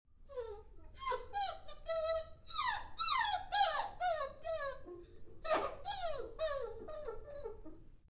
Puppy Whimpering Sound Effect Download: Instant Soundboard Button
Dog Sounds192 views